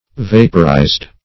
Vaporized - definition of Vaporized - synonyms, pronunciation, spelling from Free Dictionary
Vaporize \Vap"o*rize\ (v[a^]p"[-o]*r[imac]z or